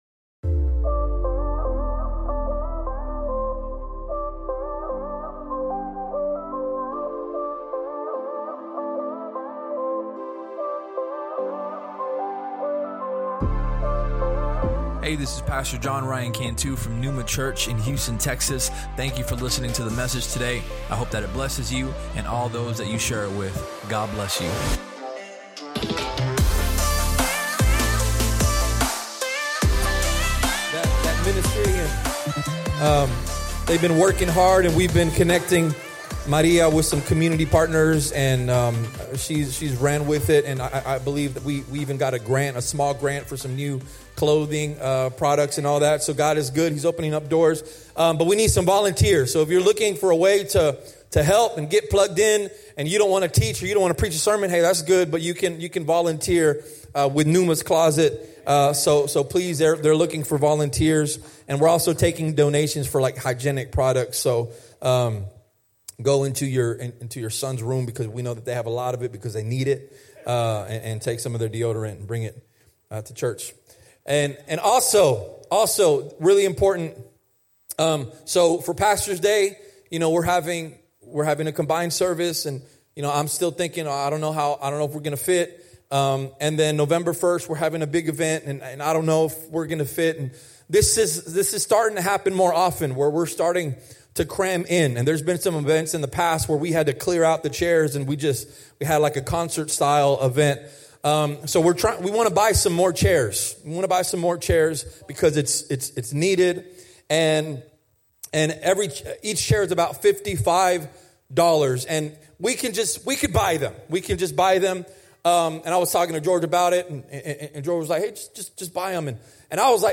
Sermon Topics: Revival, Zeal If you enjoyed the podcast, please subscribe and share it with your friends on social media.